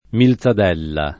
milzadella
[ mil Z ad $ lla ]